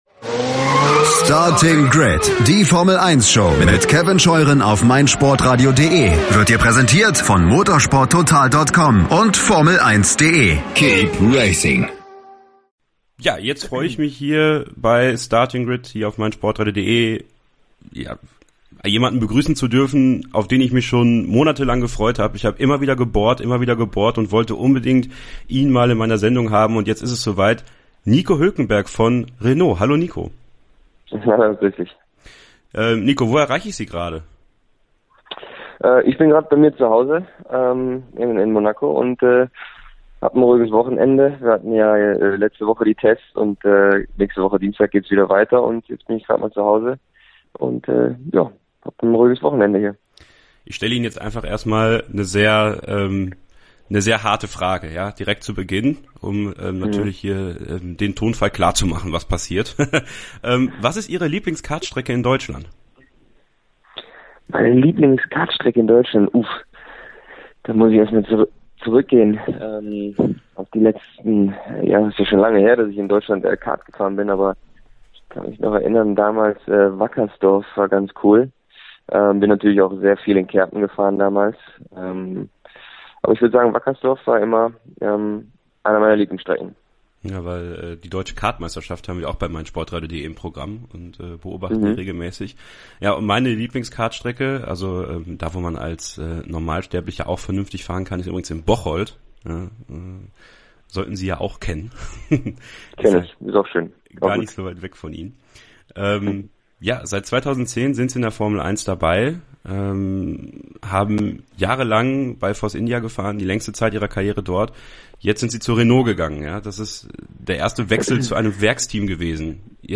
Starting Grid: Hülkenberg im Exklusiv-Interview ~ Starting Grid Podcast
starting-grid-extra-nico-huelkenberg-im-interview-vor-der-zweiten-testwoche.mp3